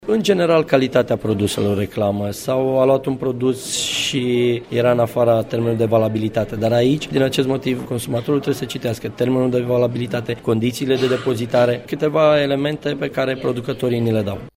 La Autoritatea Naţionala pentru Protecţia Consumatorului se înregistrează, pe an, de 5 ori mai multe reclamaţii pentru produsele bancare decât cele pentru produse alimentare, a declarat preşedintele instituţiei, Marius Dunca, în cadrul unei conferinţe pe teme de comunicare în industria alimentare.